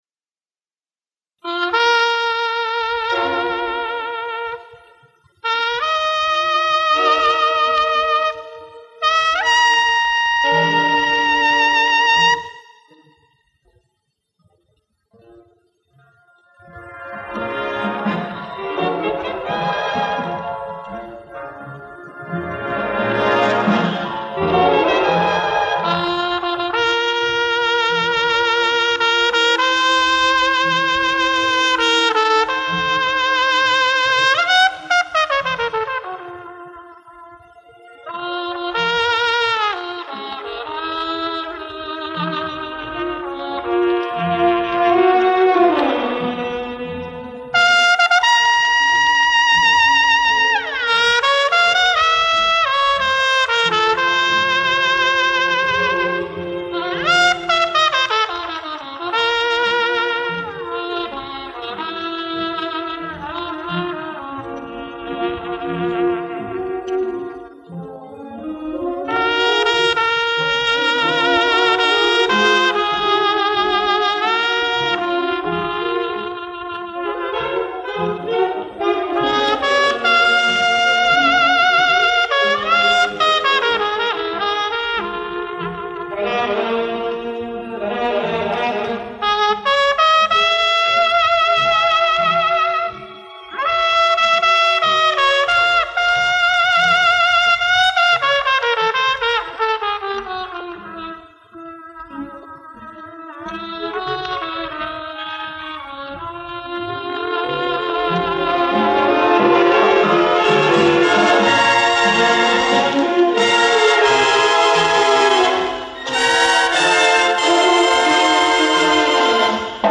Соло на трубе.